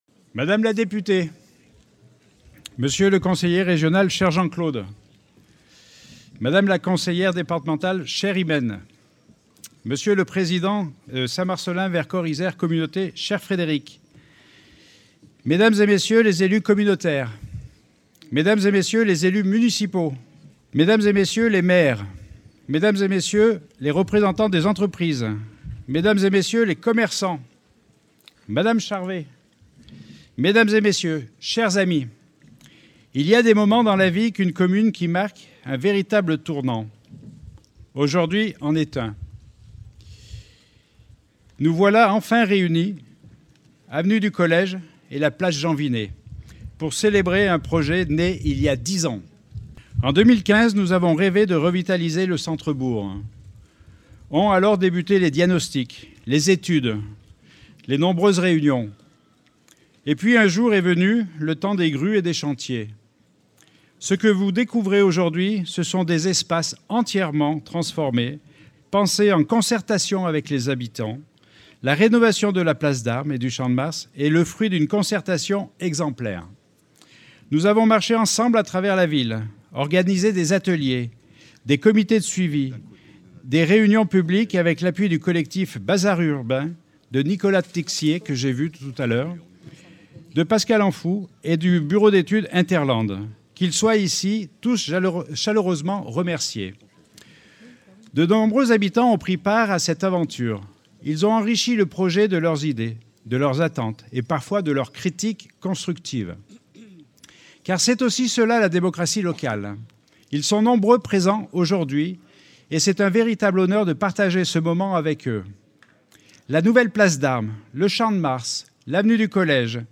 Inauguration des travaux de requalification du centre-ville de Saint Marcellin